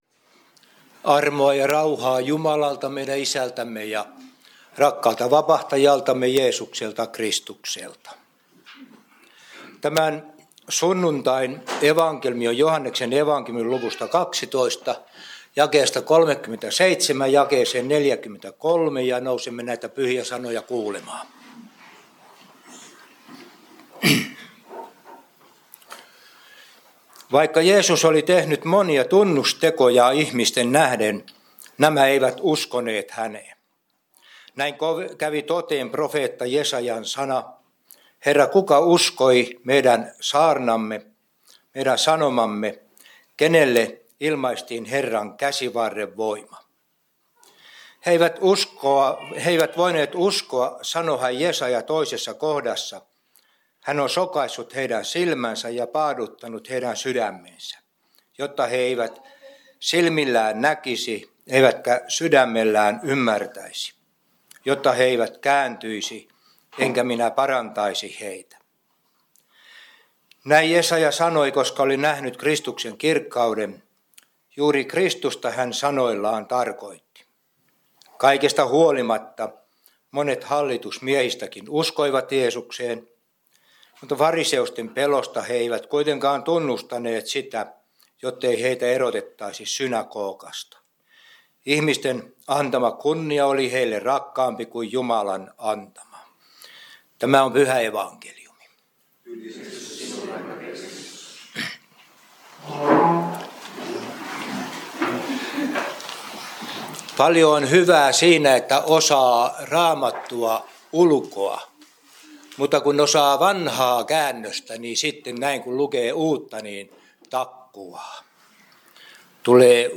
Kokoelmat: Seinäjoen Hyvän Paimenen kappelin saarnat